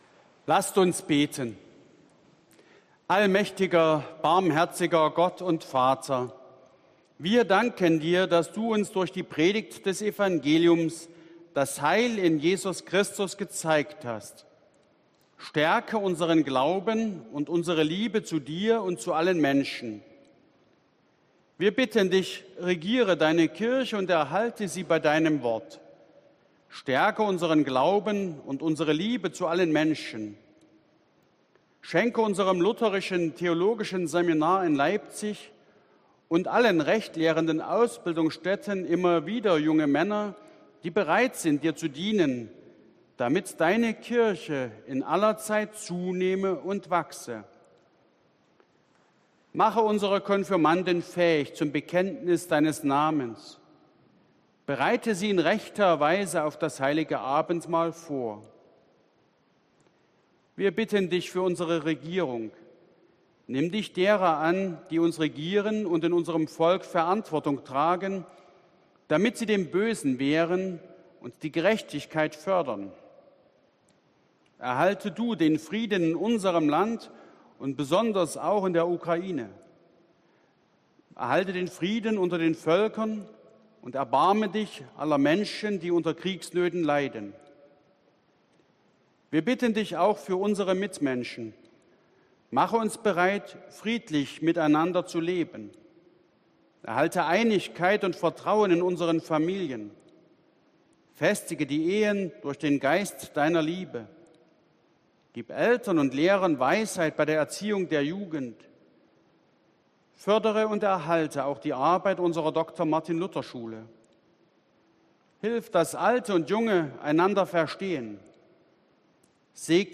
Orgelstück zum Ausgang
Audiomitschnitt unseres Gottesdienstes vom Sonntag Septuagesimä 2022.